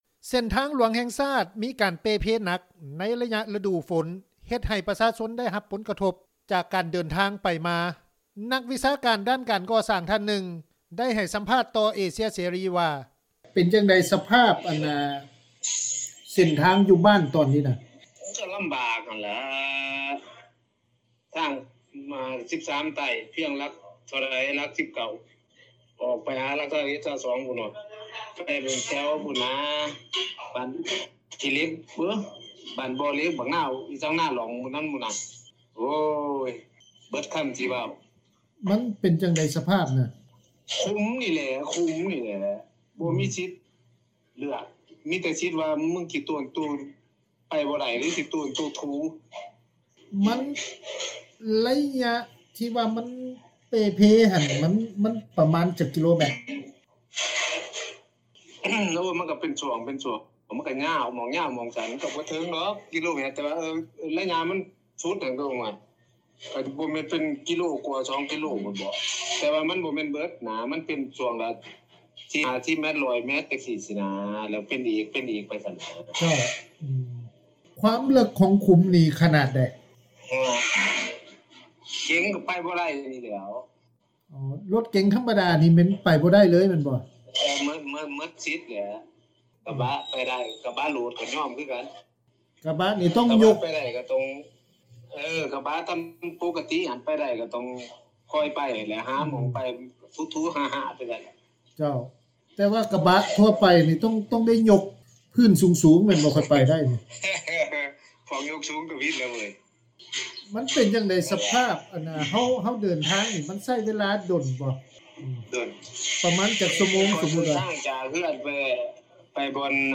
ປະຊາຊົນ ທ່ານ ນຶ່ງ ໄດ້ໃຫ້ສໍາພາດ ຕໍ່ວິທຍຸ ເອເຊັຽເສຣີວ່າ:
ປະຊາຊົນ ອີກທ່ານນຶ່ງ ໄດ້ໃຫ້ສໍາພາດ ຕໍ່ວິທຍຸ ເອເຊັຽເສຣີ ວ່າ: